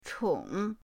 chong3.mp3